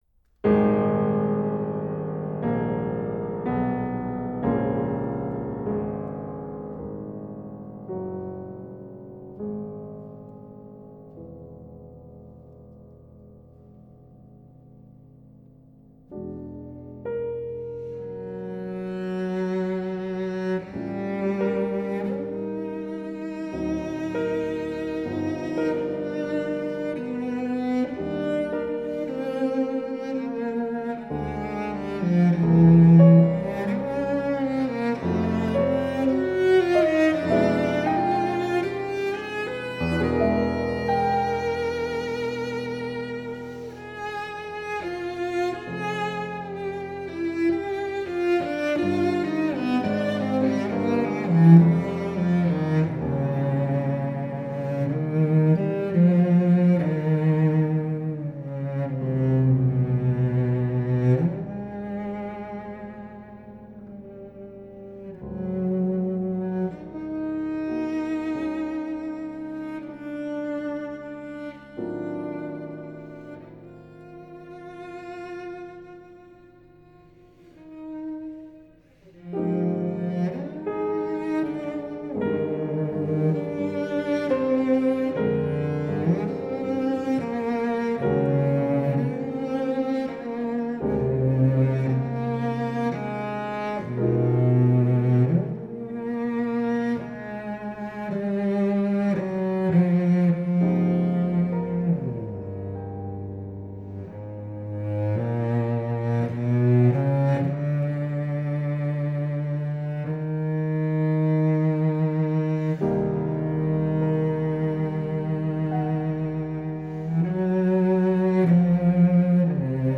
cello